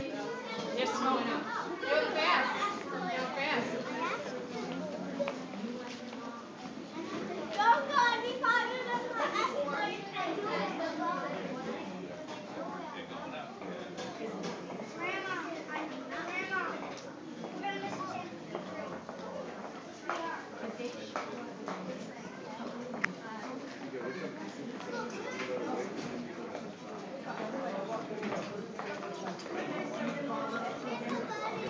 field recording 1
location: waiting room at the hicksville train station
sounds heard: voices of people that blend together into incomrehensible noise, a parent telling her kid something goes fast, kid talking unclearly, a kid telling his grandma they will miss the 10:53 train